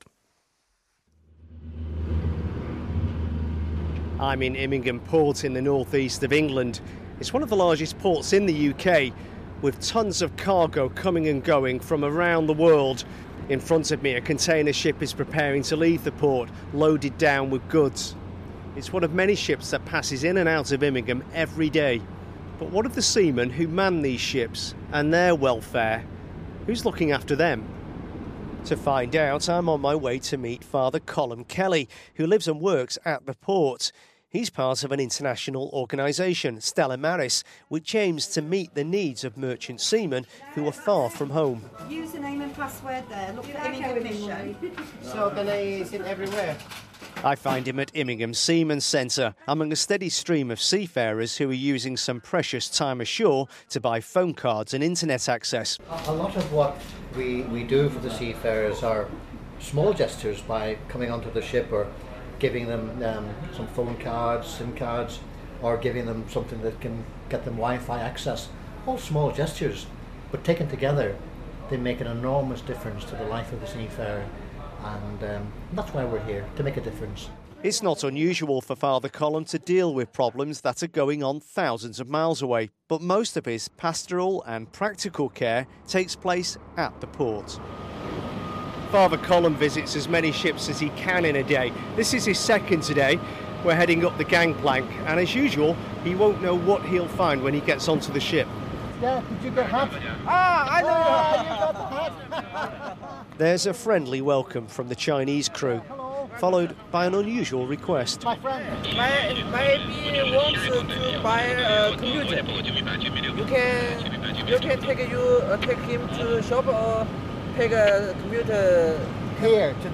Who's looking after the army of Merchant Seamen who carry most of the world's goods across the globe? World Business Report package from Immingham port. (Cuts out early).